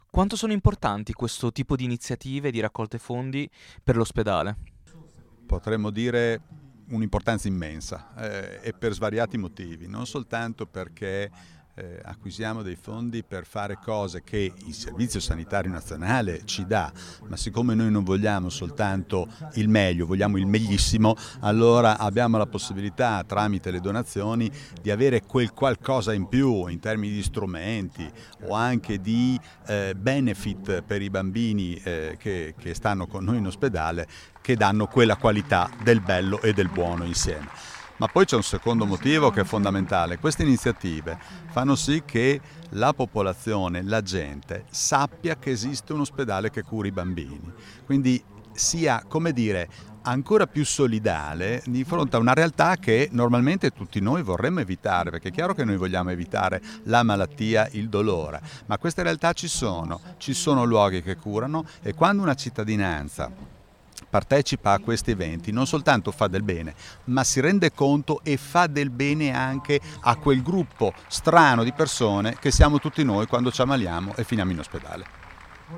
Al nostro microfono: